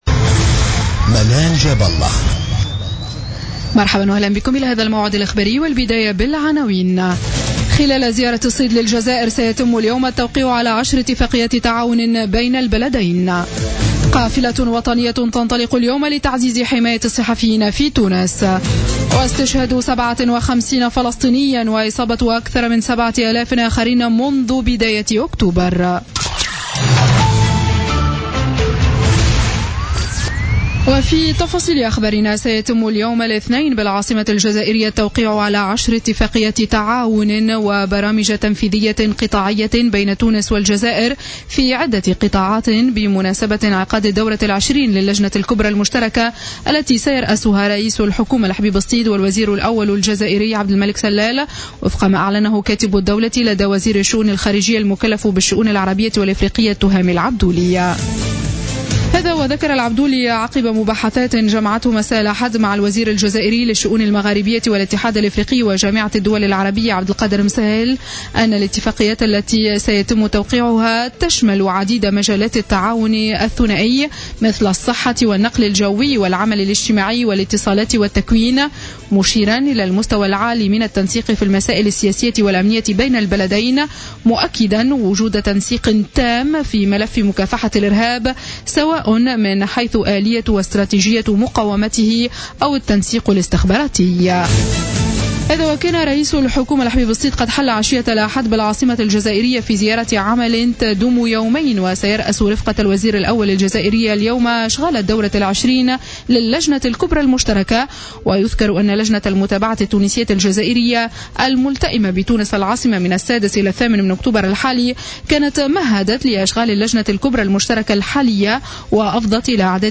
نشرة أخبار منتصف الليل ليوم الإثنين 26 أكتوبر 2015